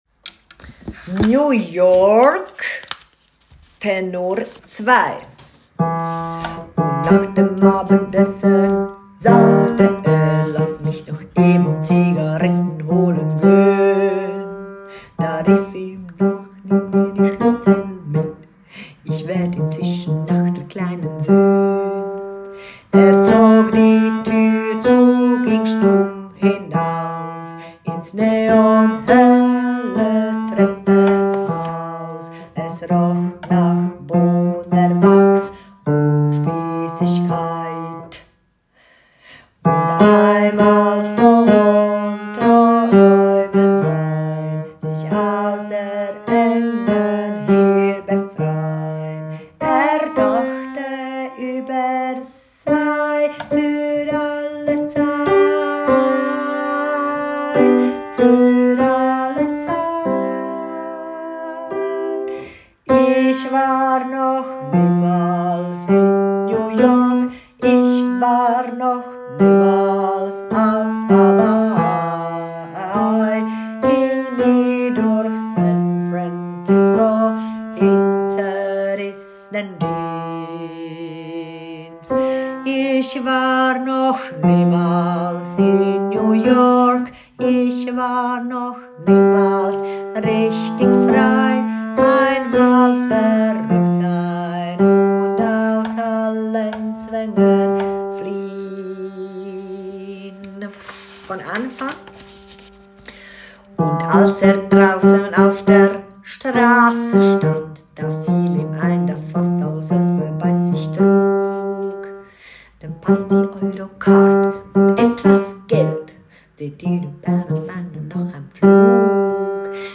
2. Tenor